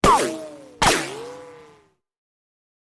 Free SFX sound effect: Ricochet.
Ricochet
Ricochet.mp3